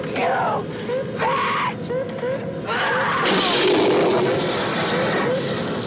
The quality is kept to a minimum because of webspace limitations.